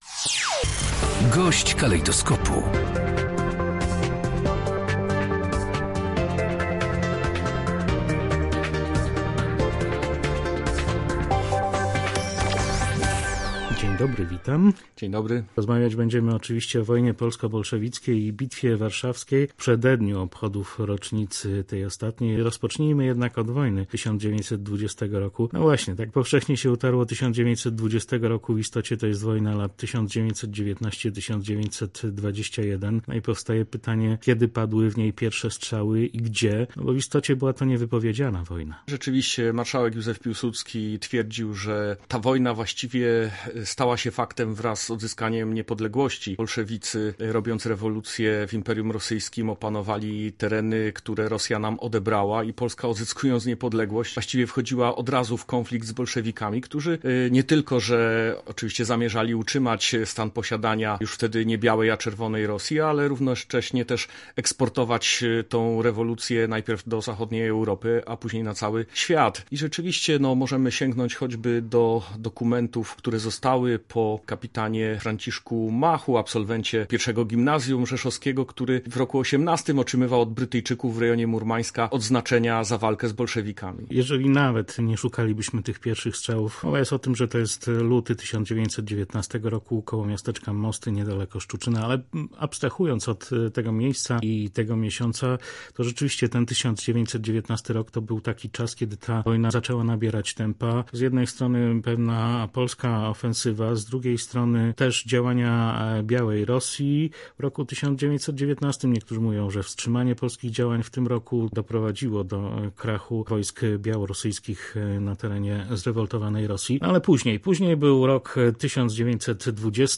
Bitwa Warszawska zatrzymała marsz bolszewików na Zachód • Audycje • Polskie Radio Rzeszów